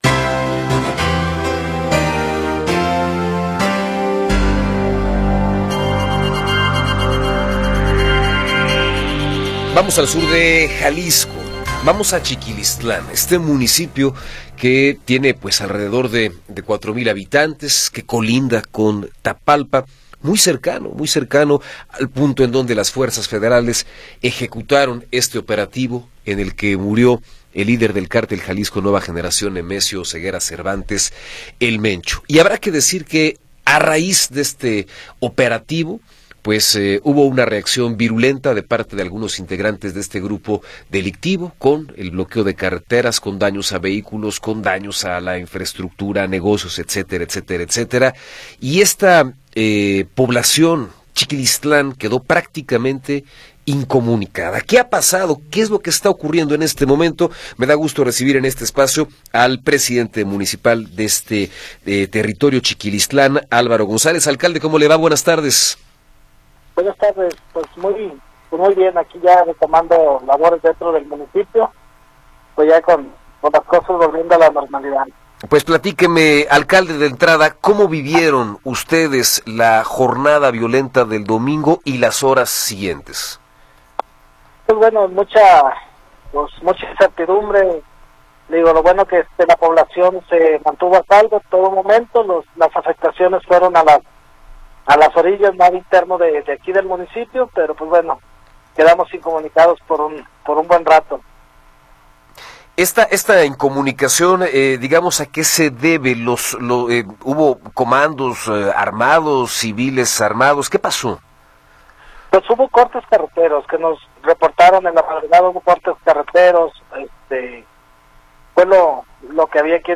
Entrevista con Álvaro González Alvarado
Álvaro González Alvarado, alcalde de Chiquilistlán, nos habla sobre la situación en el municipio tras la jornada violenta por el abatimiento de “El Mencho”.